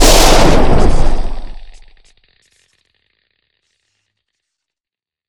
electra_blast.ogg